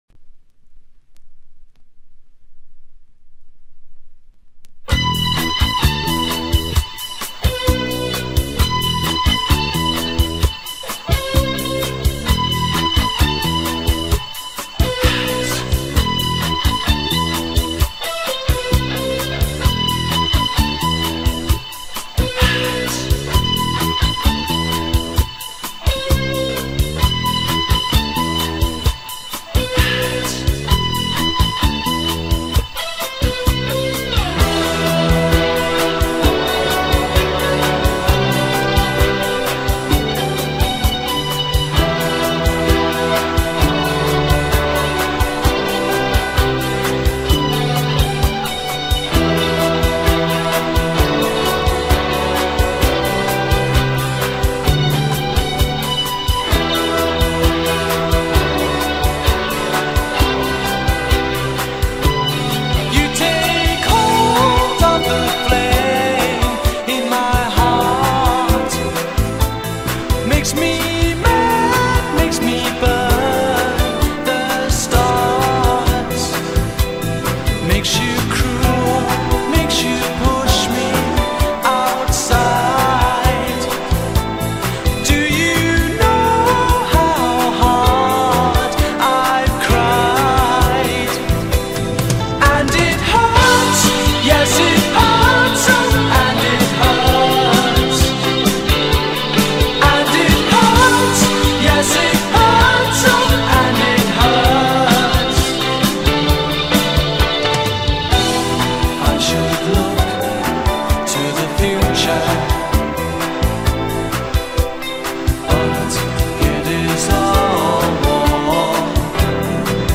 Chanson
rock, pop, new wave
on guitar
vocals